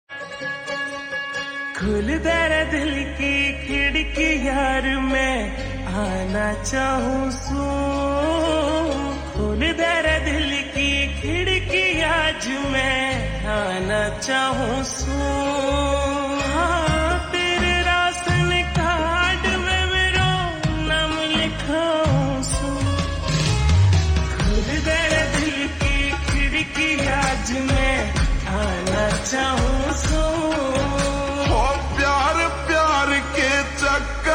Haryanvi Songs
Slow Reverb Version
• Simple and Lofi sound
• Crisp and clear sound